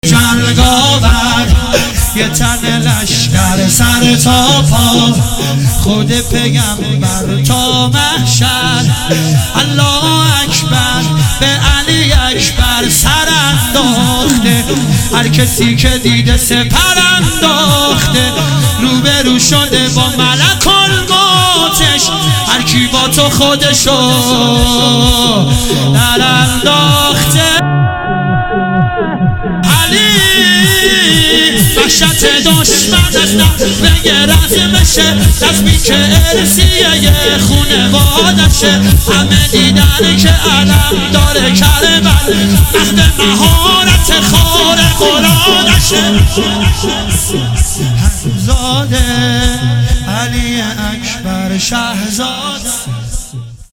مداحی شور
هیئت زوار البقیع تهران